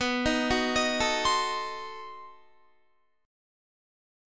B7b9 chord